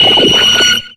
Cri de Lewsor dans Pokémon X et Y.